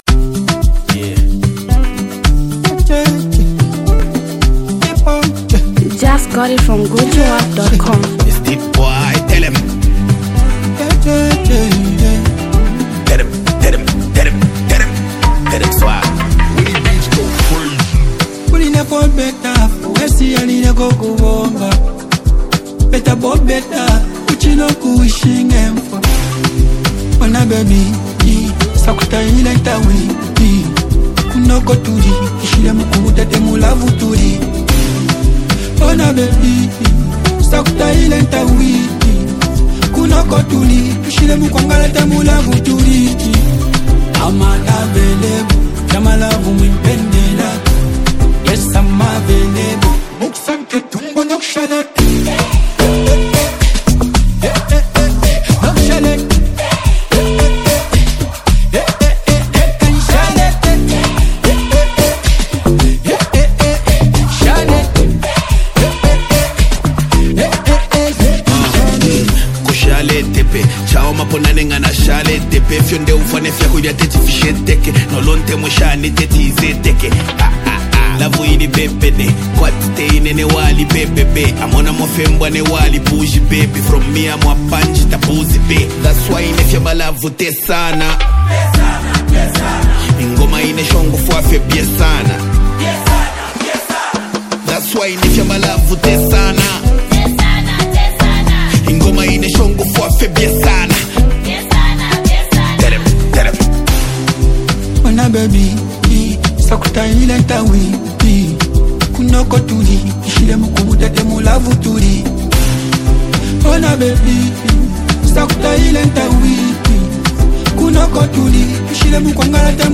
Zambian new music